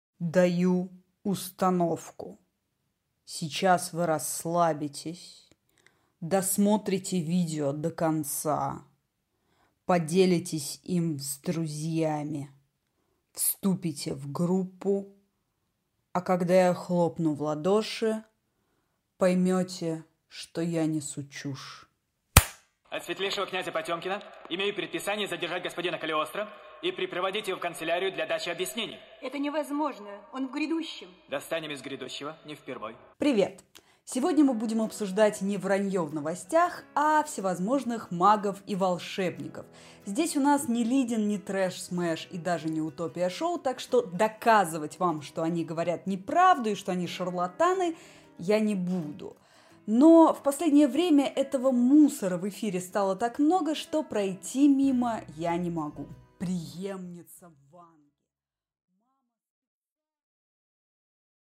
Аудиокнига Экстрасенсы вернулись в эфир | Библиотека аудиокниг